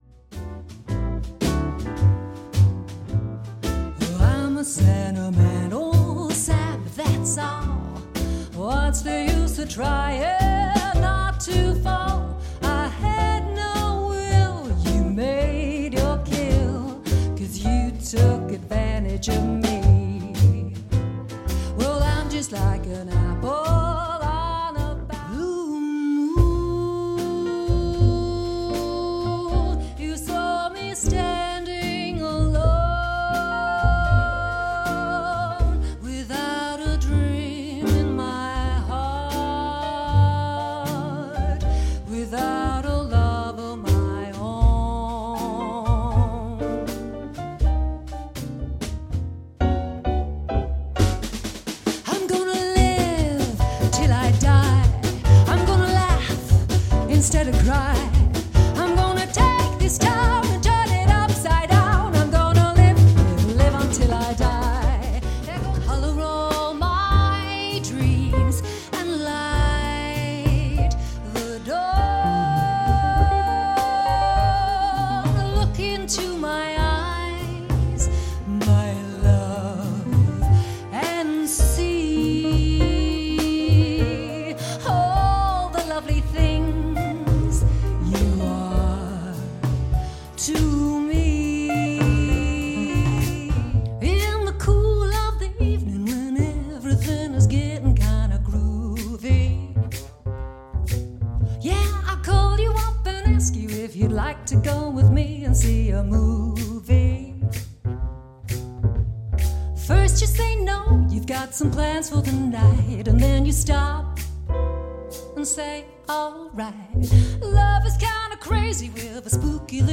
Sophisticated jazz band with distinct Celtic flavours.
Female Vocals, Keys, Double Bass, Drums